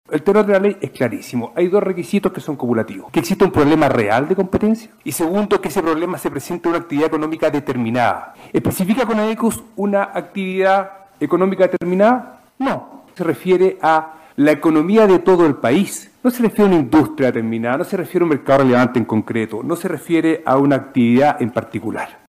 Se trató de una de las audiencias más esperadas del año, donde participaron abogados representantes de los grandes grupos económicos que operan en el país.